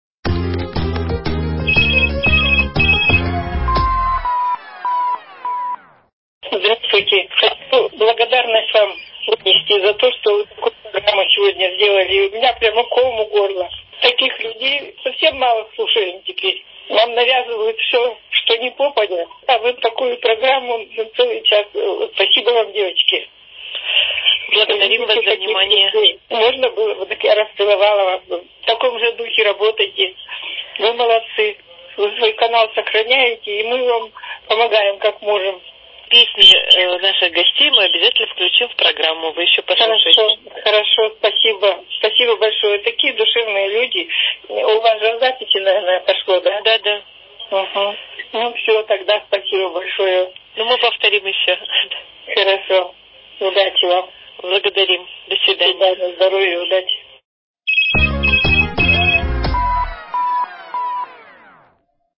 Рубрика «Обратная связь». Отзыв иркутянки на передачу с участием артистов из Москвы Бориса Галкина и Инны Разумихиной.